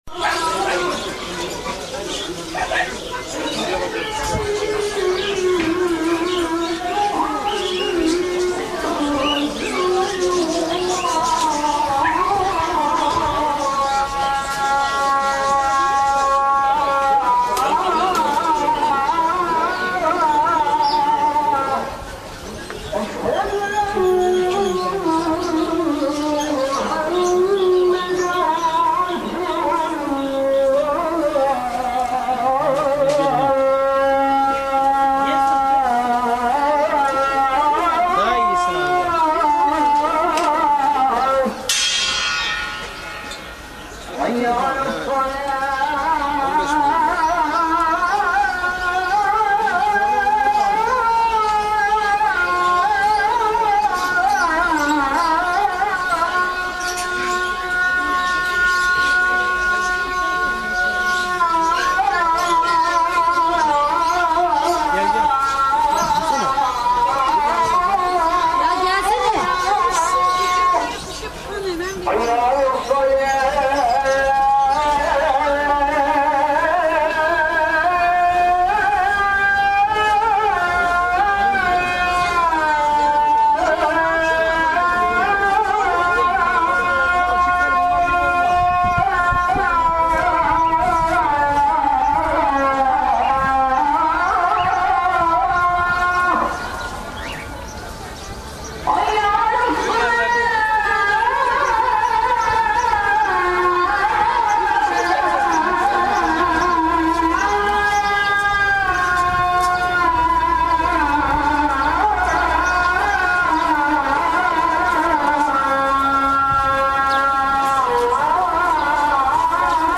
- на стамбульском базаре, мы идем в той части, где птичий рынок. С мечети Эминону - крик муэдзина. Очень глючно, кстати - ну, экзотика.
muedzin.mp3